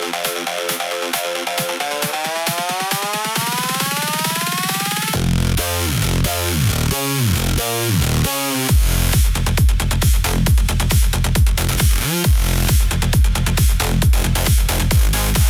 Cyberpunk EDM